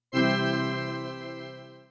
an unobtrusive, bland, scene separator.
Stingers.